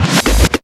NOISY HATS.wav